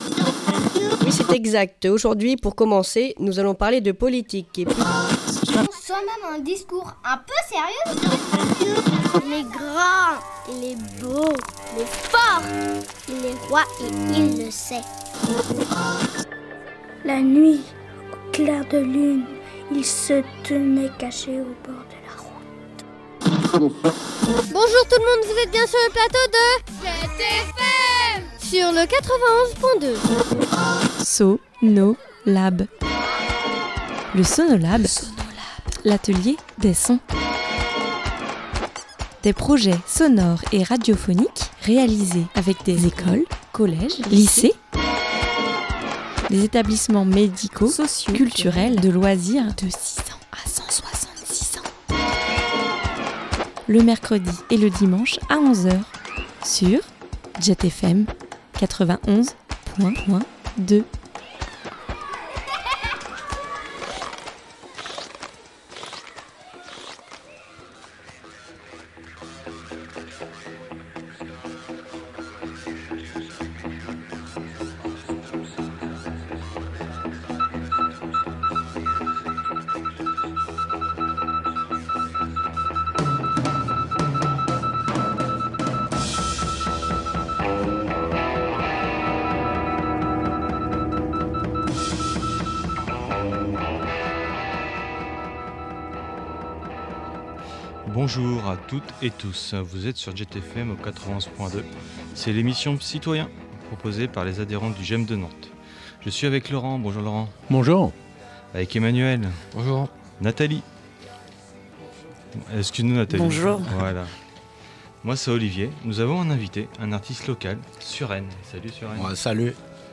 Un atelier radio en collaboration avec Jet a lieu tous les quinze jours, pour que les adhérents qui le souhaitent préparent cette émission.